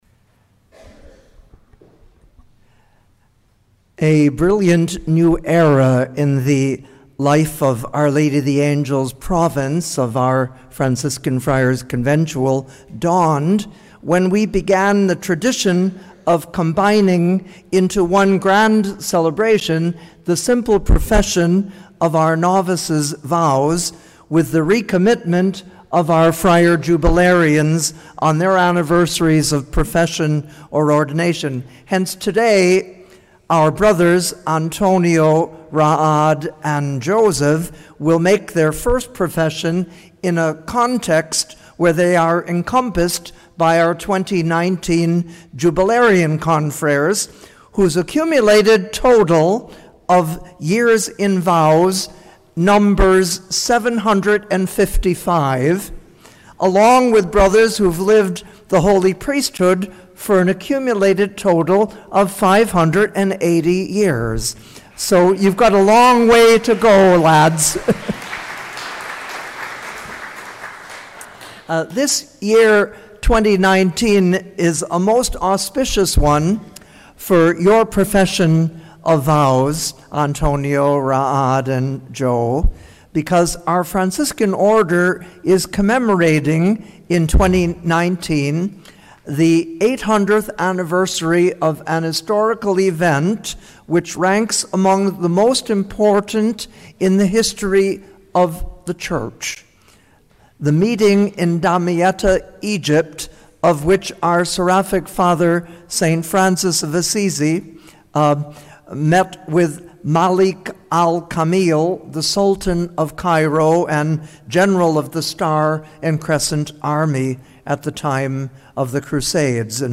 Homily-July-22nd.mp3